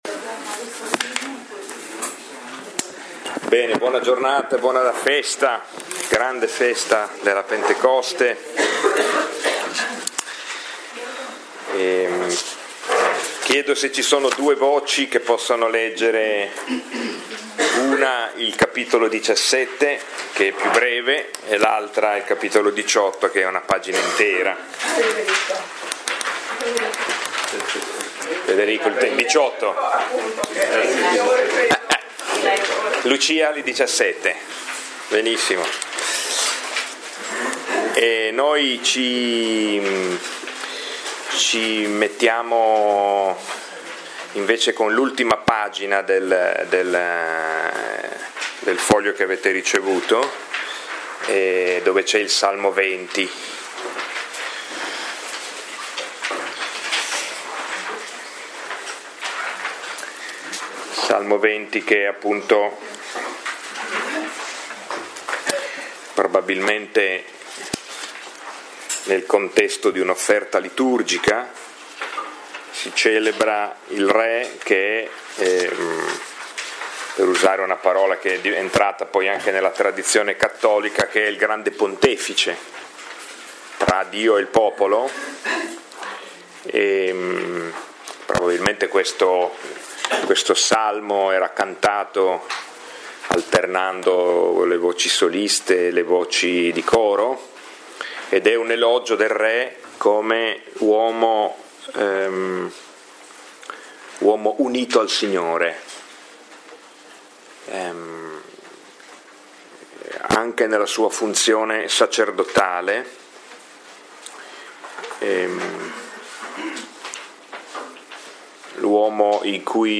Lectio 8 – 20maggio 2018